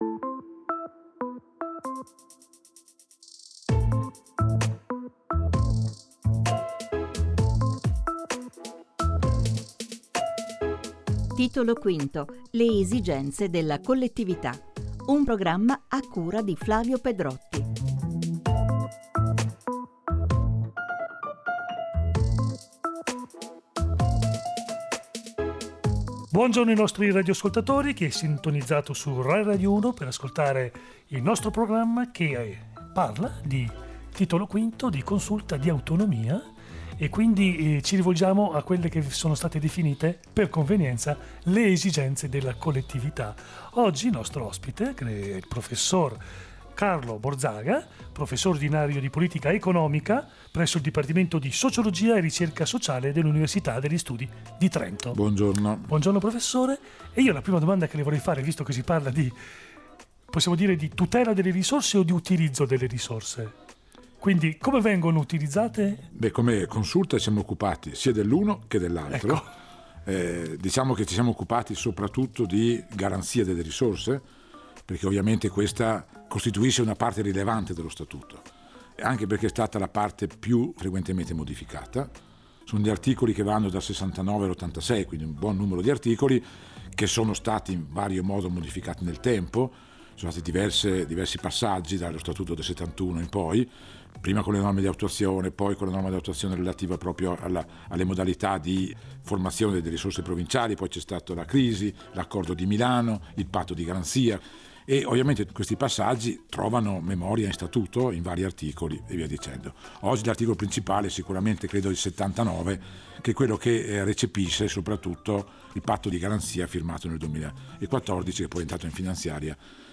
/ Notiziario radiofonico / News / RIFORMA DELLO STATUTO -